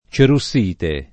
cerussite [ © eru SS& te ] s. f. (min.)